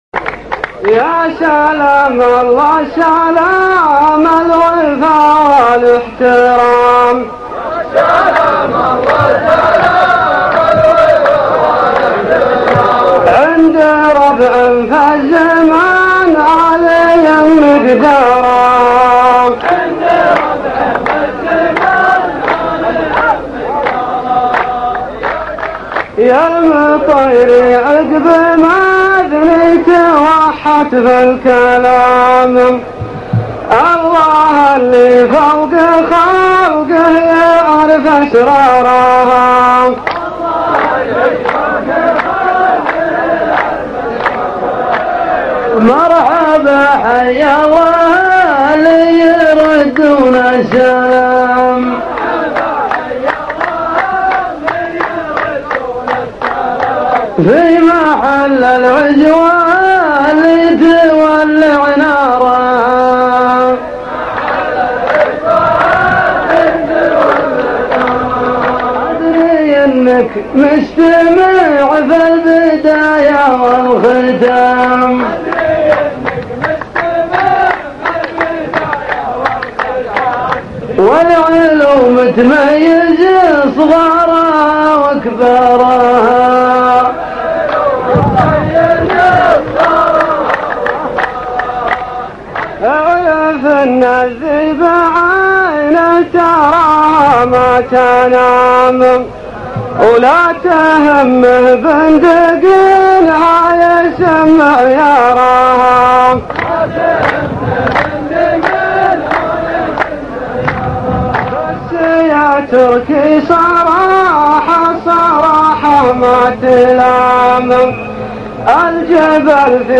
محاوره صوتية